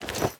equip_diamond1.ogg